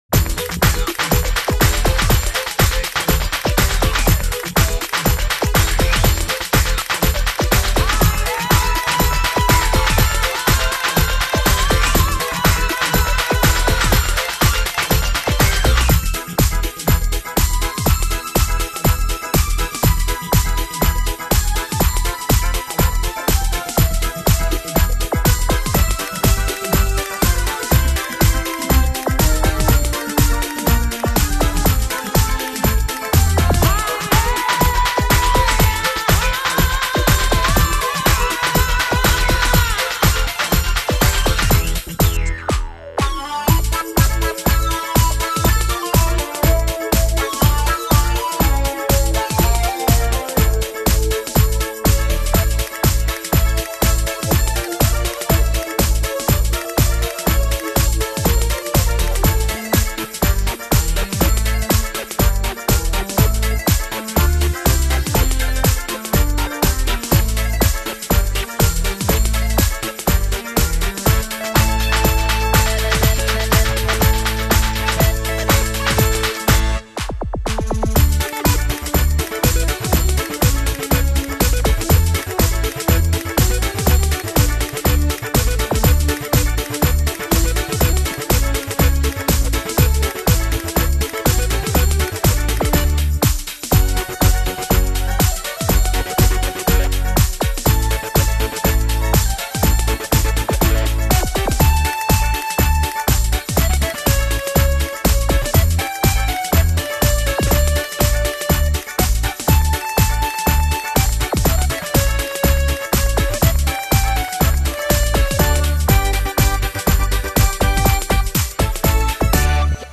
01 Paso Double